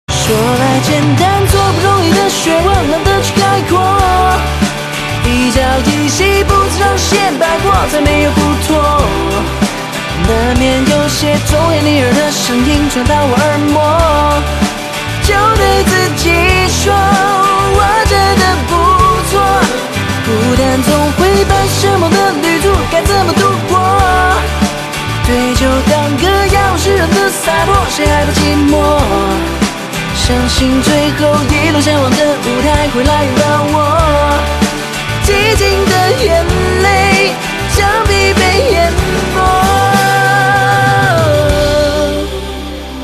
M4R铃声, MP3铃声, 华语歌曲 54 首发日期：2018-05-15 00:47 星期二